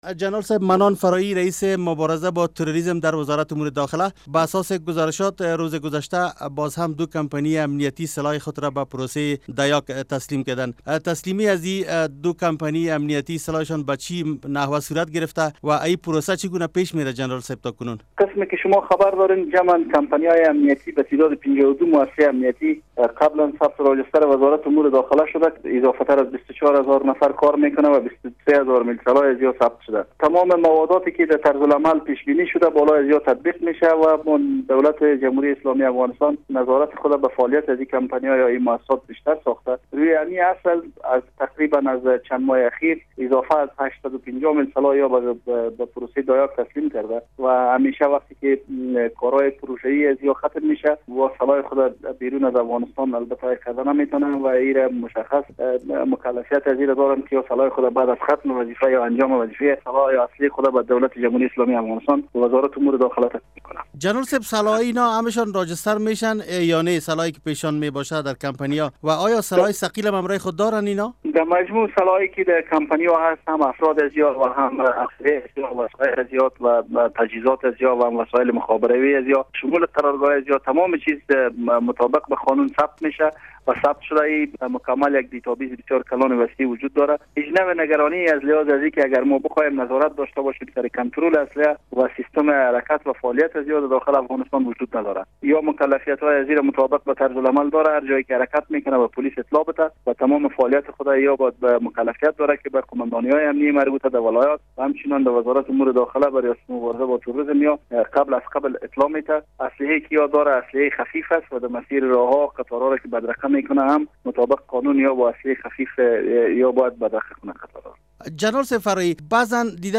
مصاحبه با جنرال منان فراهی در مورد تسلیمی اسلحه دو کمپنی امنیتی خصوصی به پروسهء دایاگ